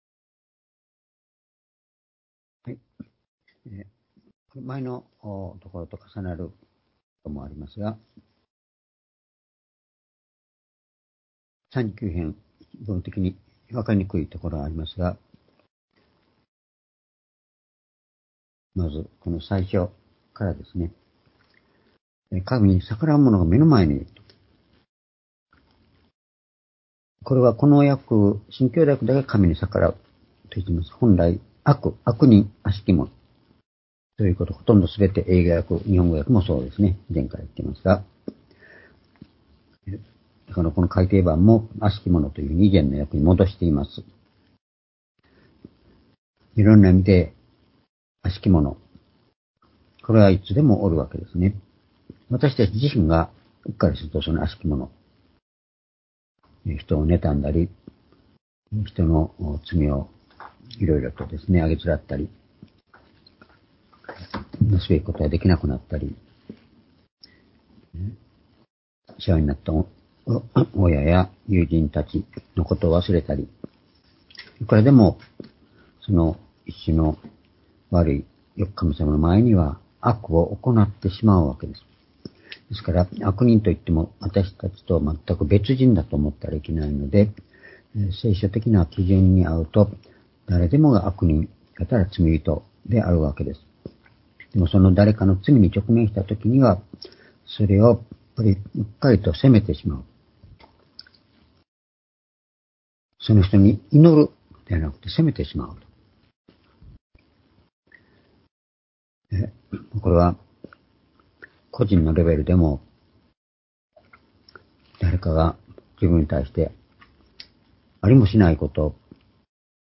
（主日・夕拝）礼拝日時 ２０２４年1月2日（夕拝） 聖書講話箇所 「沈黙の中の祈り」 39の8-14 ※視聴できない場合は をクリックしてください。